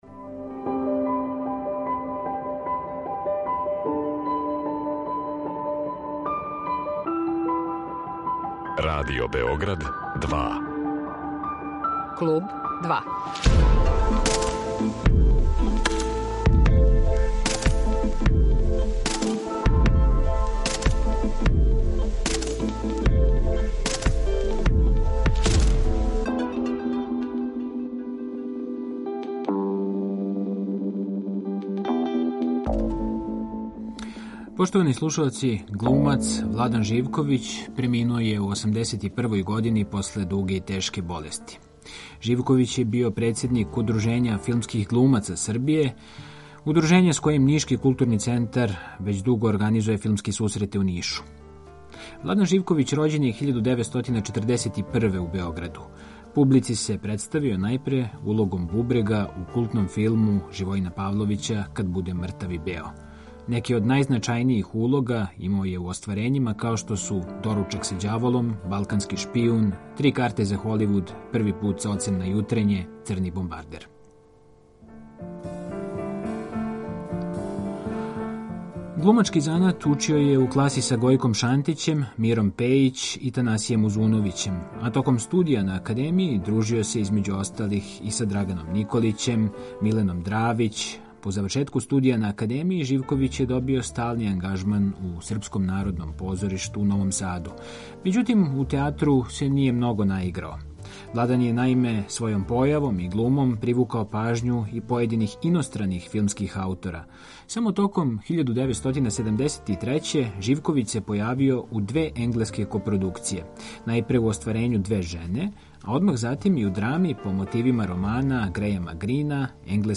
Одиграо је улоге у више од 700 радиодрамских остварења и награђиван је Плакетом и Повељом „Витомир Богић" нашег Драмског програма. Једном приликом се за наш програм присетио бројних анегдота, значајних редитеља, тон-мајстора, глумаца и лектора са којима је сарађивао.